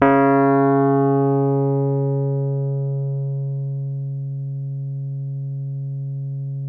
RHODES CL07L.wav